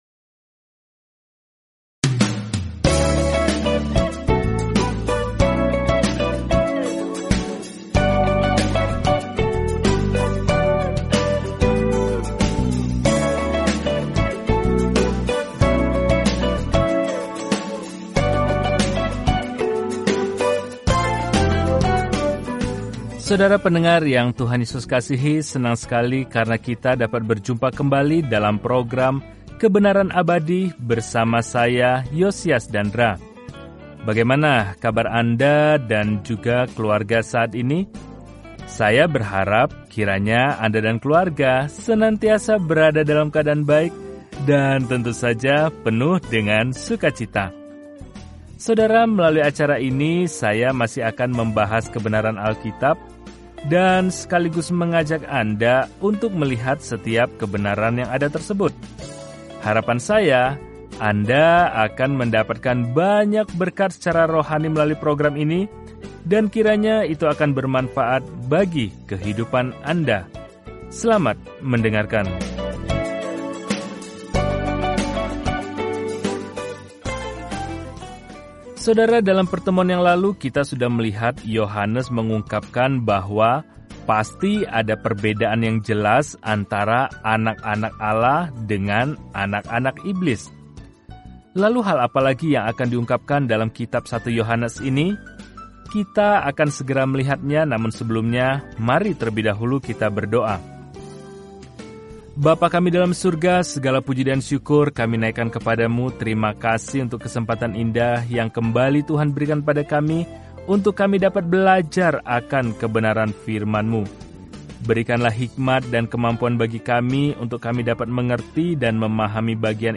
Firman Tuhan, Alkitab 1 Yohanes 3:11-15 Hari 15 Mulai Rencana ini Hari 17 Tentang Rencana ini Tidak ada jalan tengah dalam surat pertama Yohanes ini – kita memilih terang atau gelap, kebenaran daripada kebohongan, cinta atau benci; kita menganut salah satunya, sama seperti kita percaya atau menyangkal Tuhan Yesus Kristus. Telusuri 1 Yohanes setiap hari sambil mendengarkan pelajaran audio dan membaca ayat-ayat tertentu dari firman Tuhan.